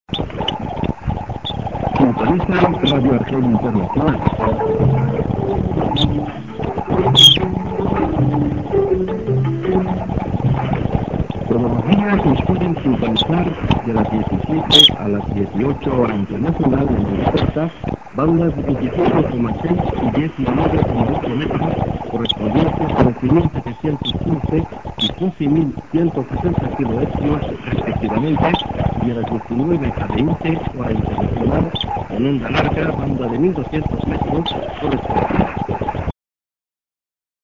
b:　->ID(man)->IS->ID+SKJ(man)->